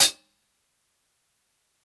635_HH_MED.wav